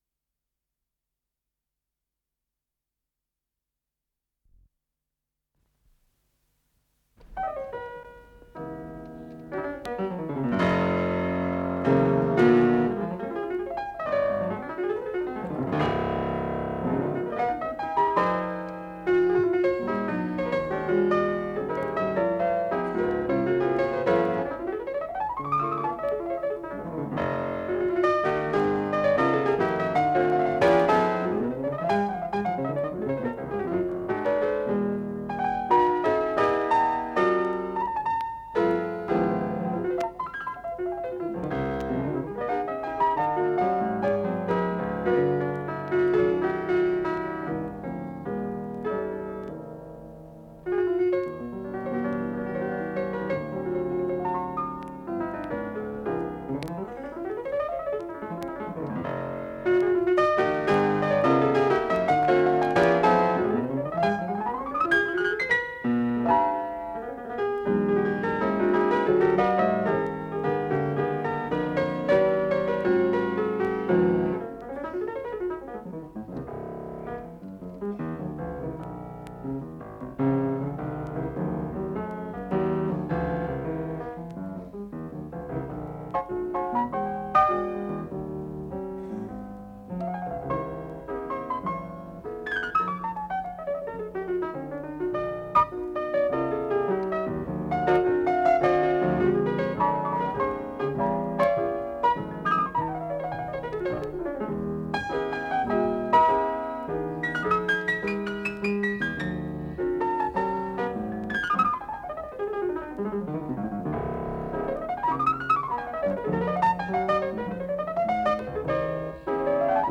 с профессиональной магнитной ленты
фортепиано
гитара
контарабс
ВариантДубль стерео